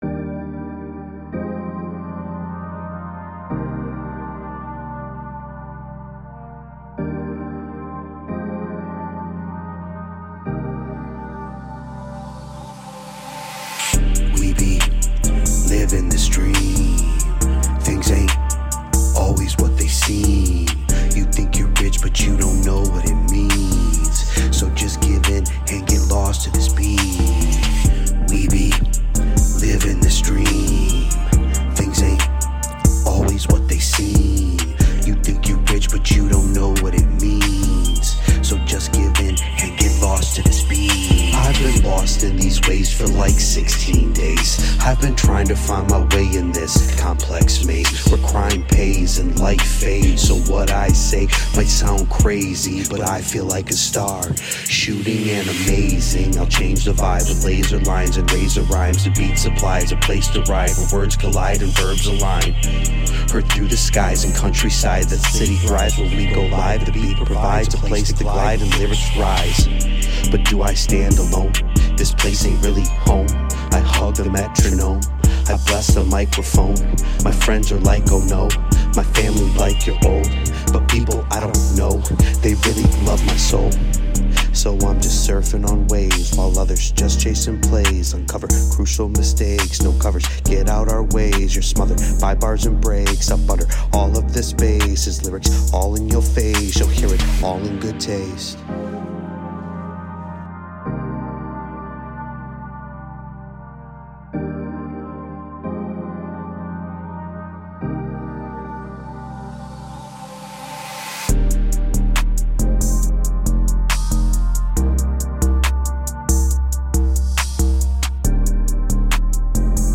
not mixed or master.. recorded on my phone..